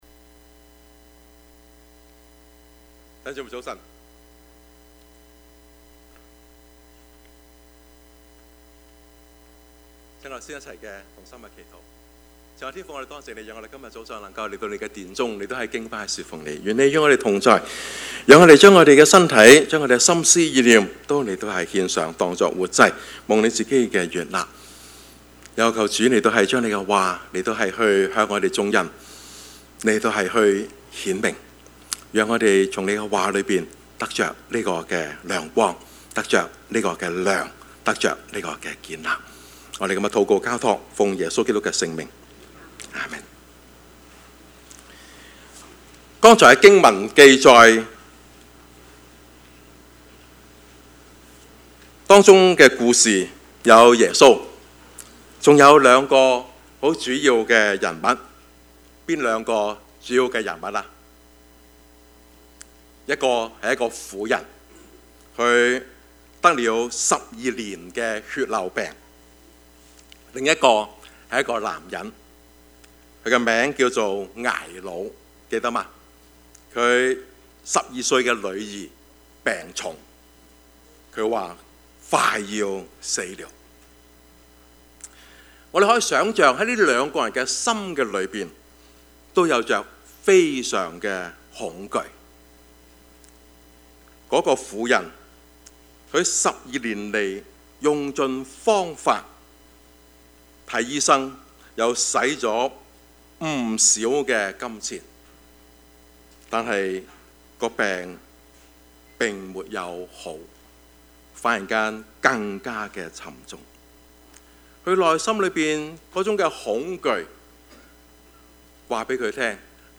Service Type: 主日崇拜
Topics: 主日證道 « 務要傳道 還要游移不定嗎?